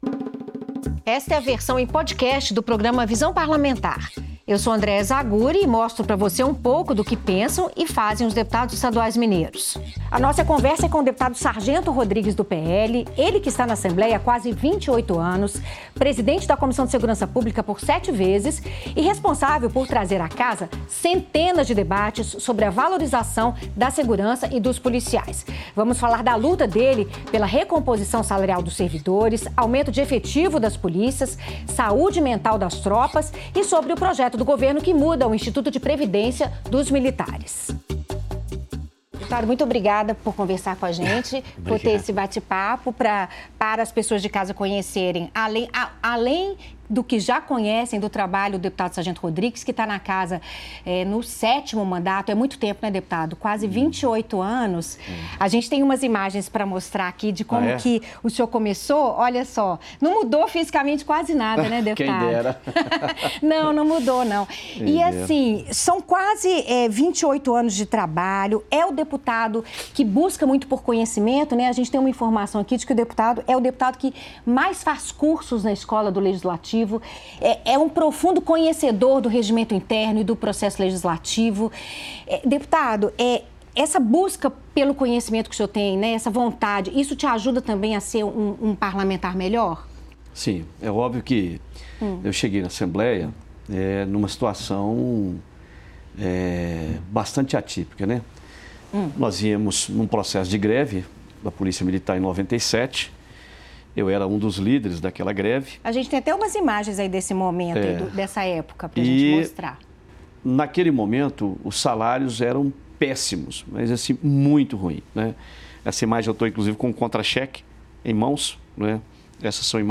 Em entrevista ao programa Visão Parlamentar, o deputado Sargento Rodrigues (PL) relembra o início de sua trajetória política.